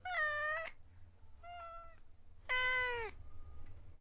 cat-sound